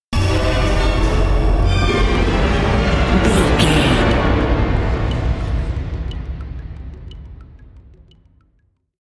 Atonal
D
percussion
strings
ominous
dark
suspense
haunting
creepy